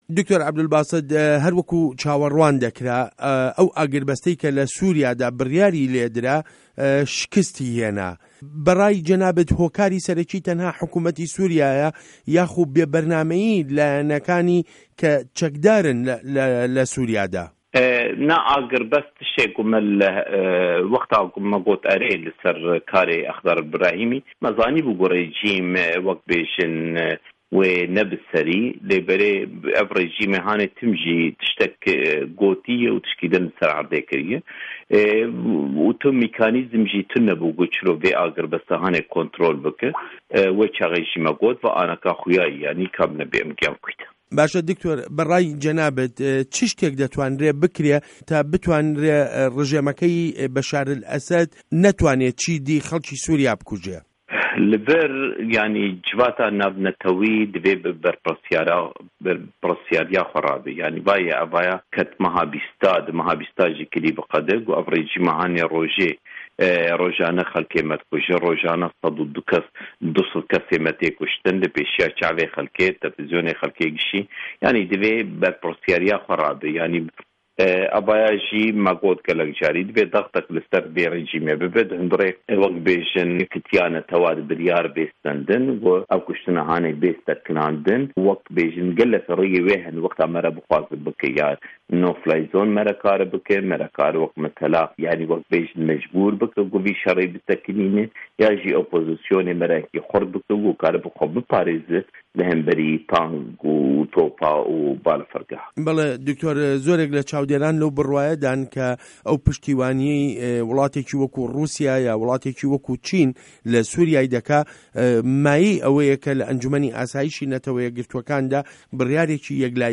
وتووێژ له‌گه‌ڵ دکتۆر عه‌بدولباست سه‌یدا